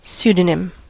pseudonym: Wikimedia Commons US English Pronunciations
En-us-pseudonym.WAV